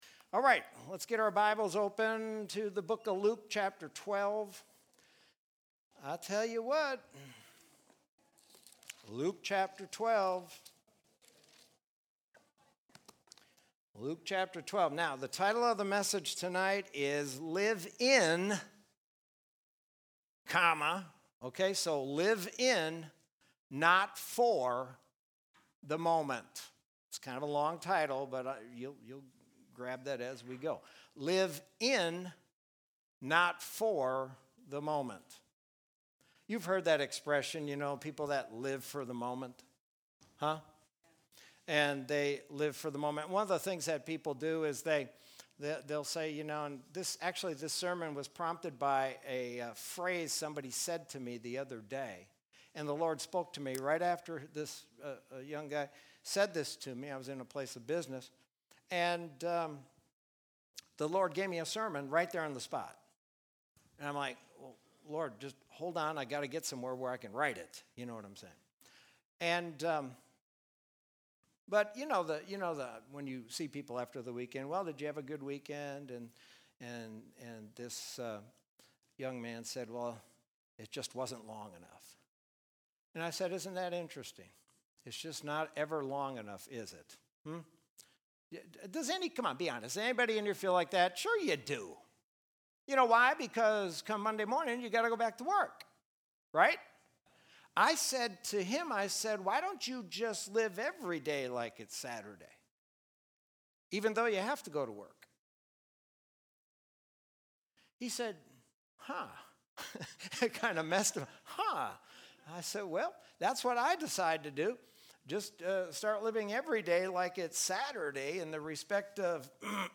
Sermon from Wednesday, June 10, 2020.